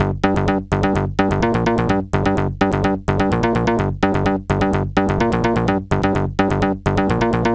Techsynth Loop.wav